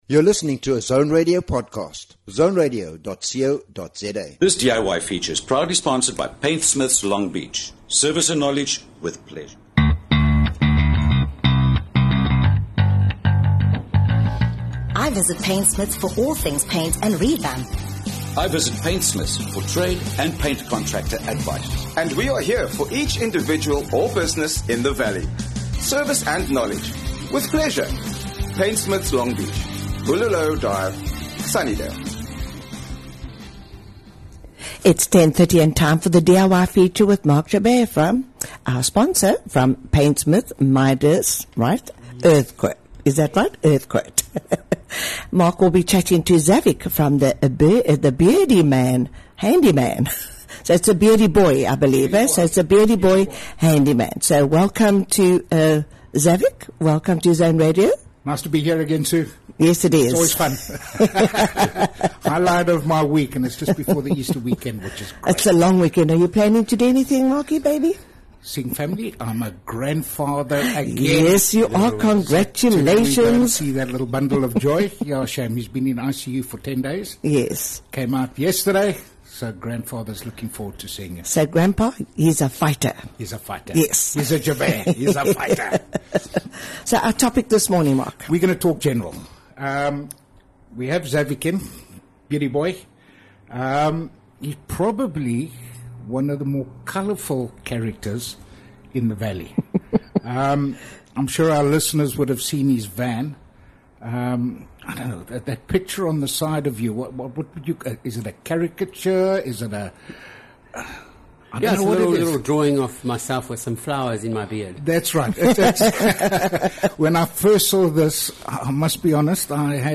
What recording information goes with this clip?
MultiMedia LIVE